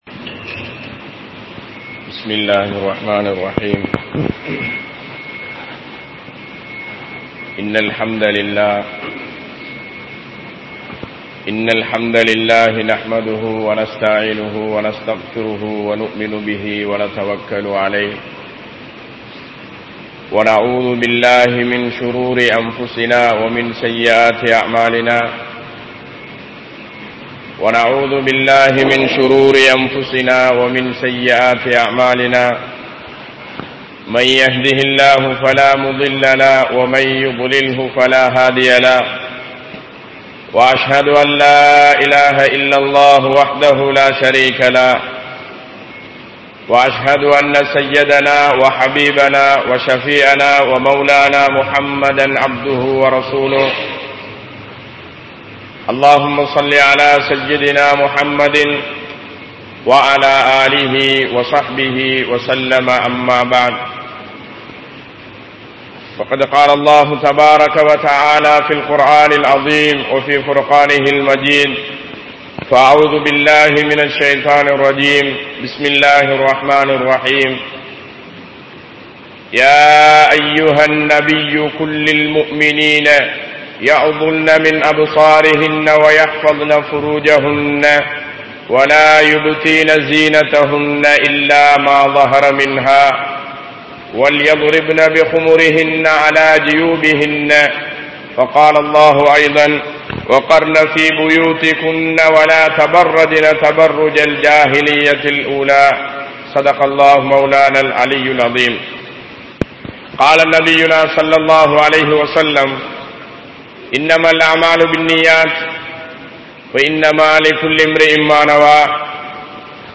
Vifachchaaraththin Kaaranihal (விபச்சாரத்தின் காரணிகள்) | Audio Bayans | All Ceylon Muslim Youth Community | Addalaichenai